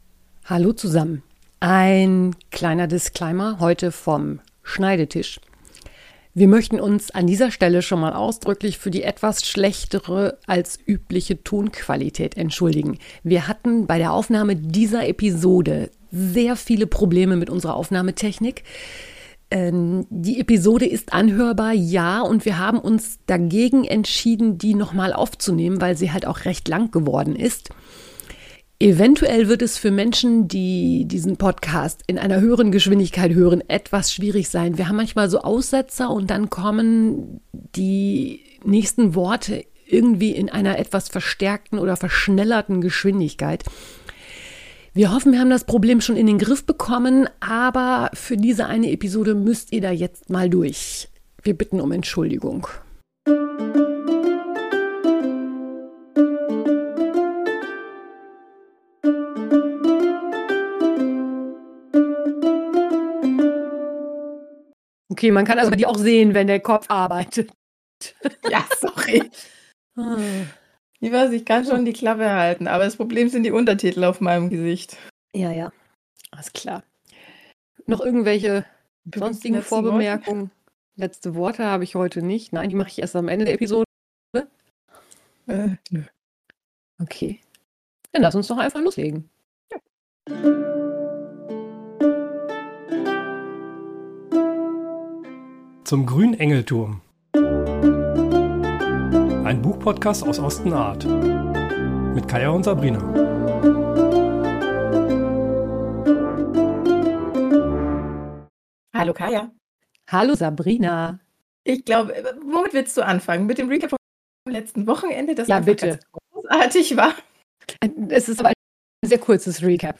Hier geht es um die Kapitel 11 und 12: Die Gebeine der Erde, Vogelflug. Leider haben wir in dieser Episode ein paar technische Probleme gehabt, wir bitten, das zu entschuldigen (die Episode kann angehört werden, ist aber tontechnisch nicht optimal - wir geloben Besserung!)